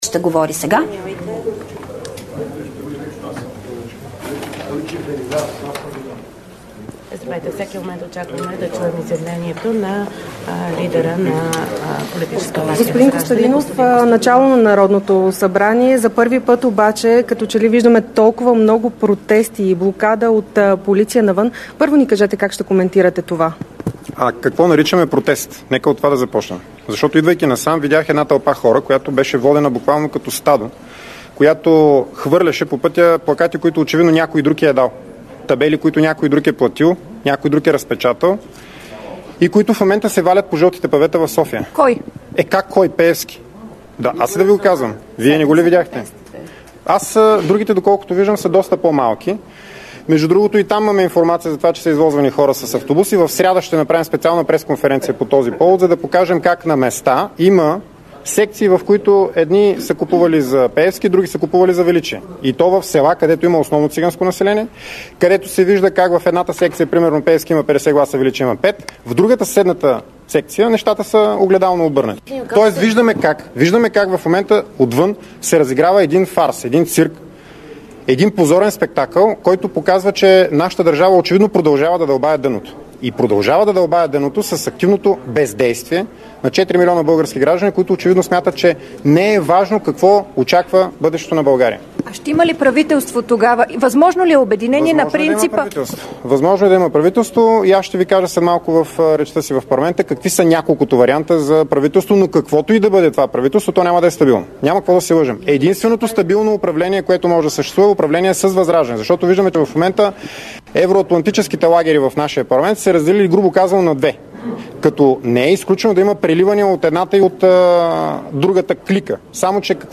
9.20 - Брифинг на председателя на „Възраждане" Костадин Костадинов. - директно от мястото на събитието (Народното събрание)